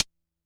stick.wav